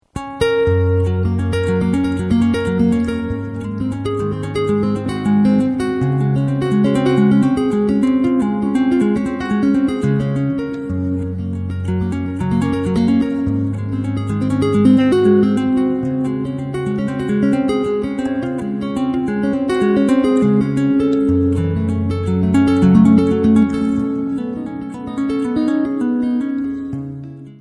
Two hand tapping specialist